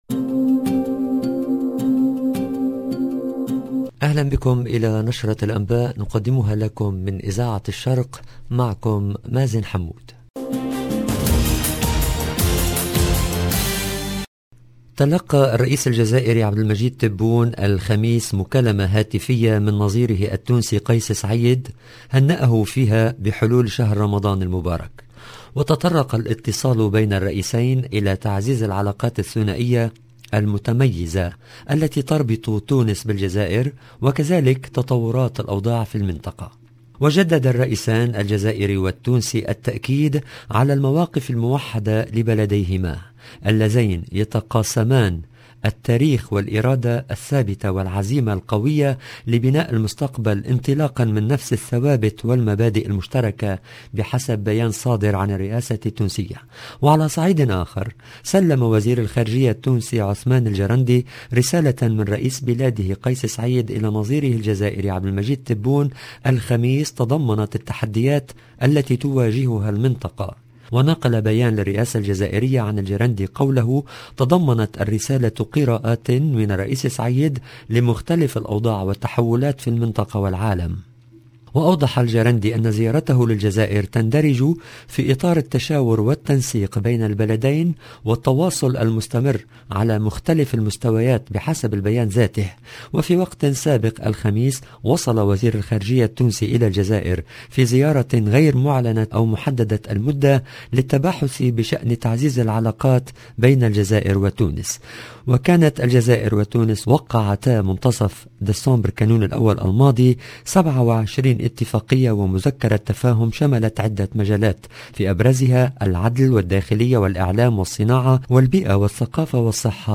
LE JOURNAL DU SOIR EN LANGUE ARABE DU 1/04/22